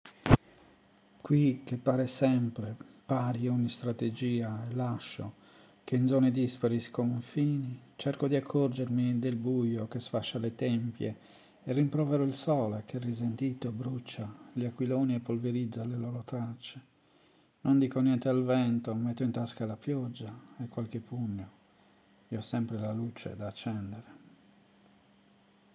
Bella la tua lettura.